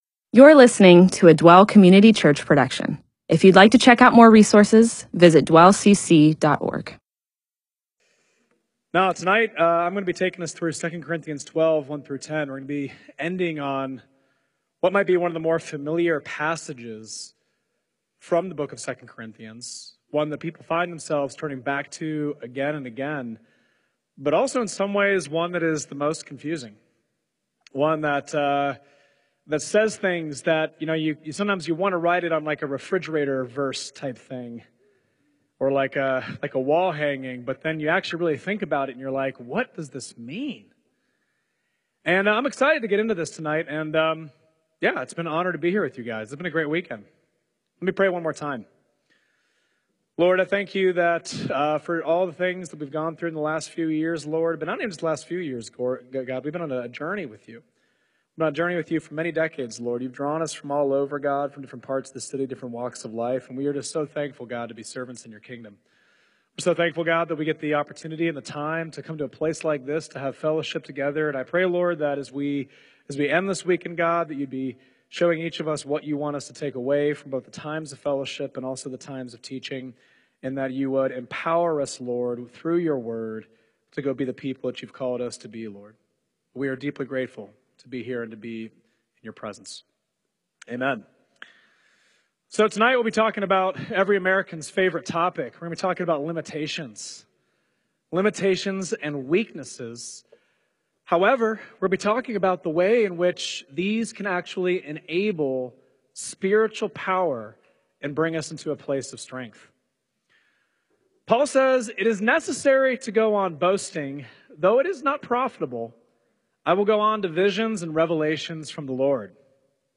MP4/M4A audio recording of a Bible teaching/sermon/presentation about 2 Corinthians 12:1-10.